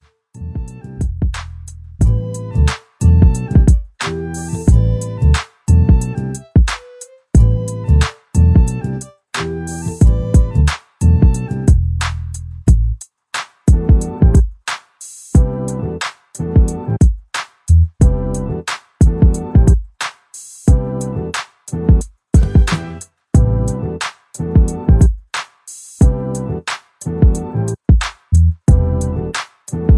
R&B with Sample and Hip Hop Beat